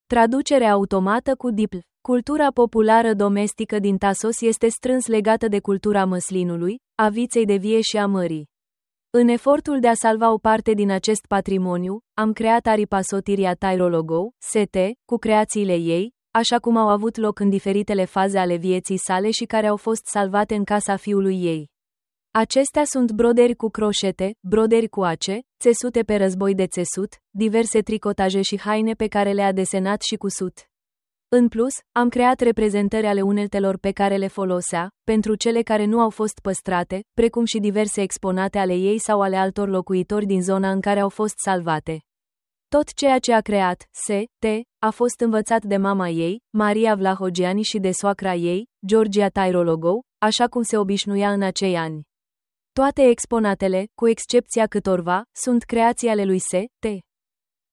Tur ghidat audio